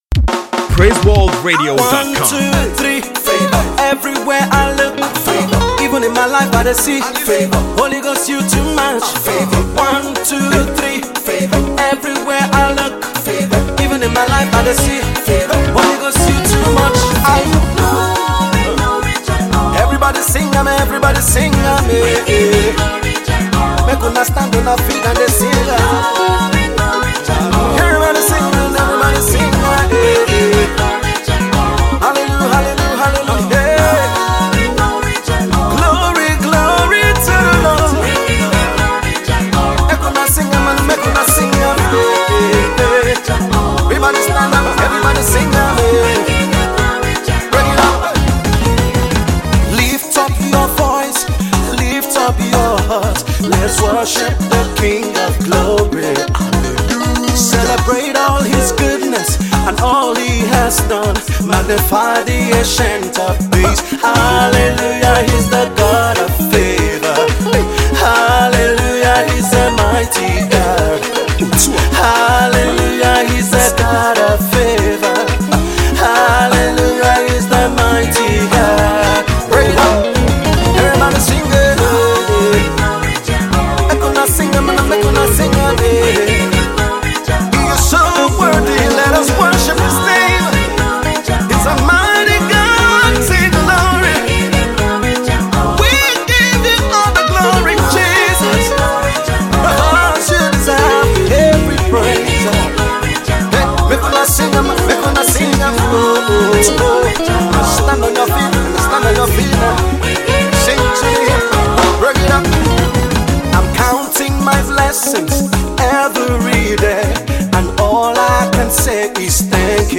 is a makossa flavored song that celebrates God’s favour.